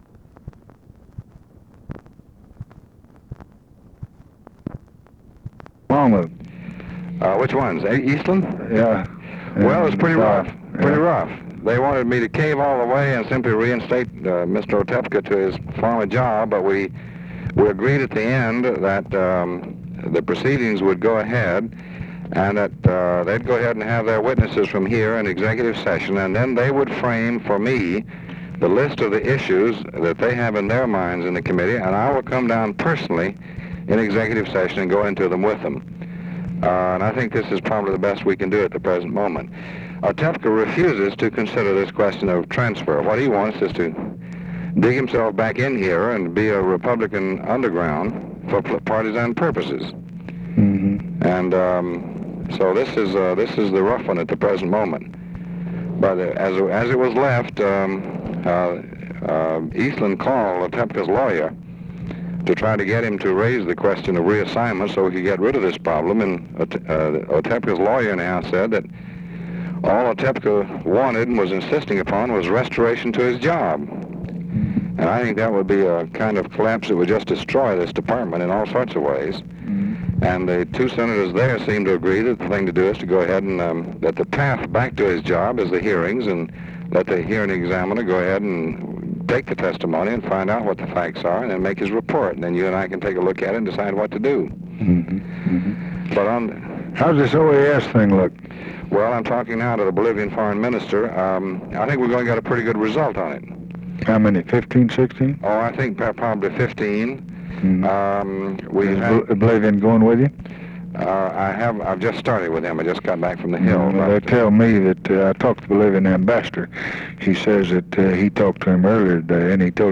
Conversation with DEAN RUSK, July 20, 1964
Secret White House Tapes